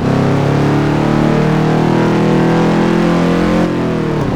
Index of /server/sound/vehicles/lwcars/dodge_daytona